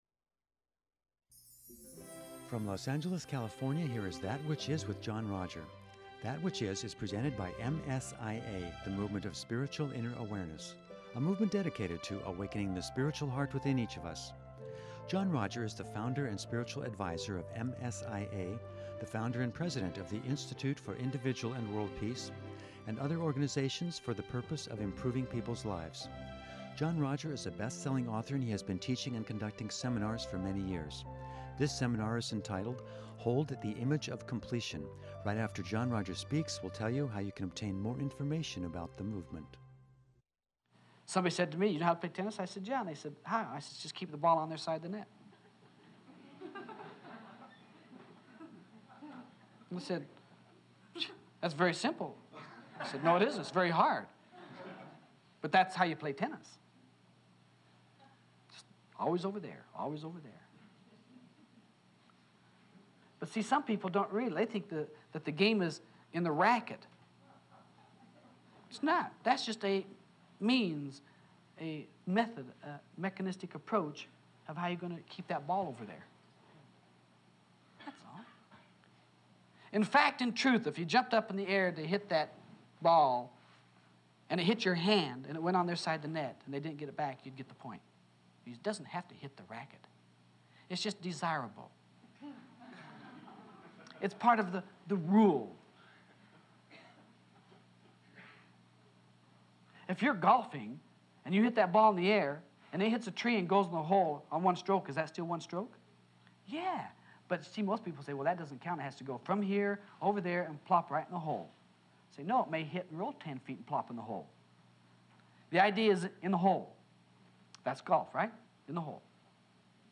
In part two of this thought-provoking seminar